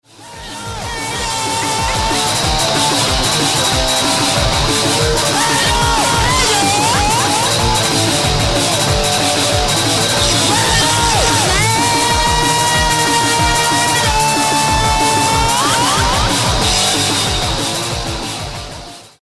experimentellen PowerPunkRockbeatz